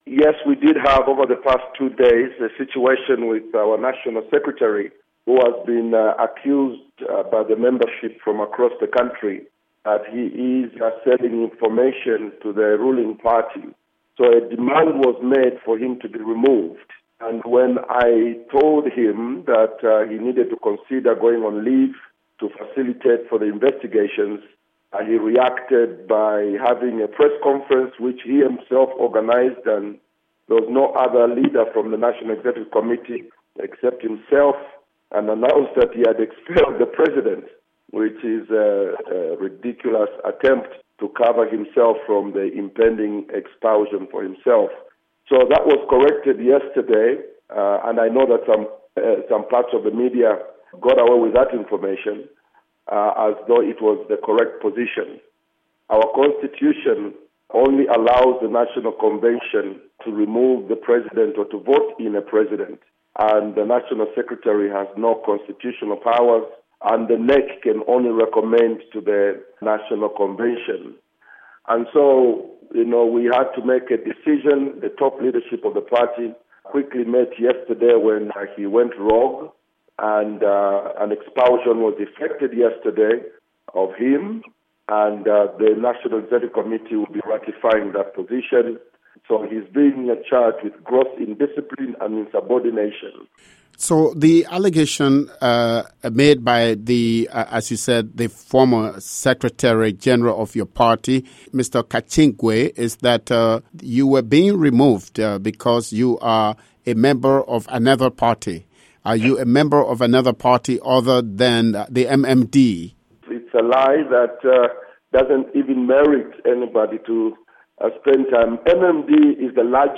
interview with Mumba